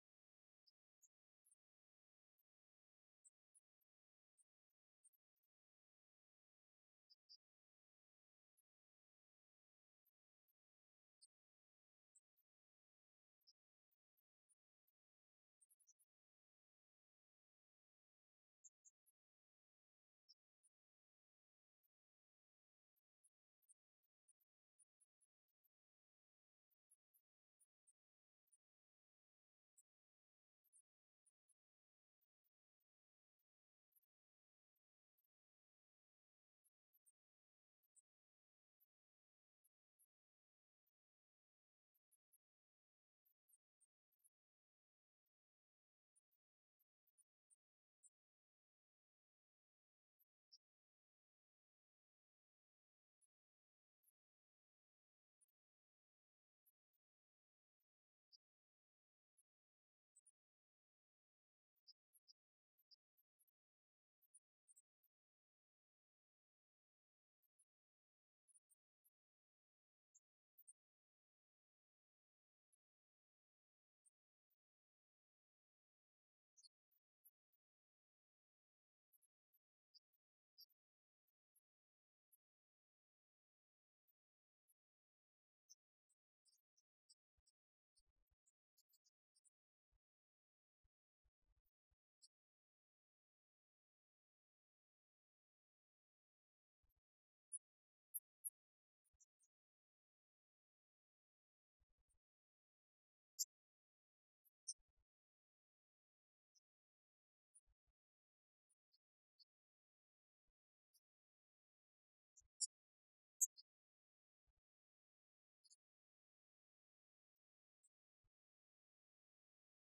Audio - Culte œcuménique - 19 Janvier 2022 à Pentemont